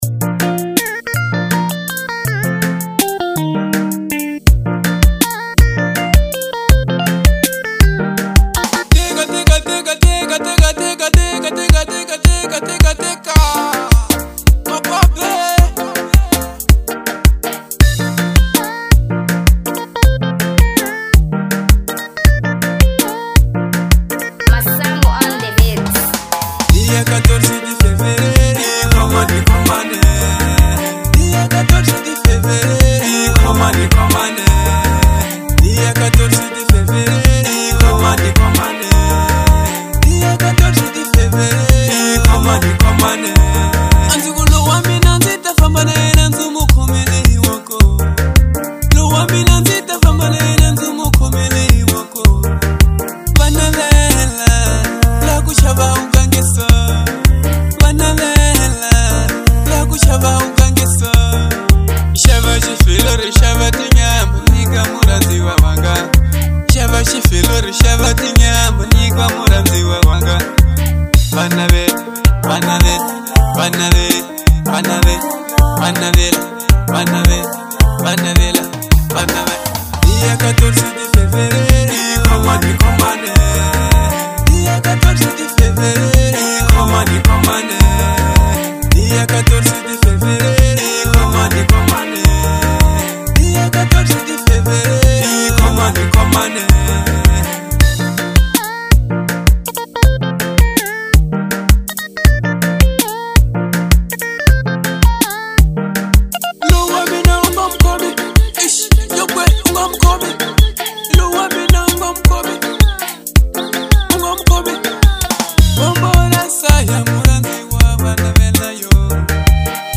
03:55 Genre : Marrabenta Size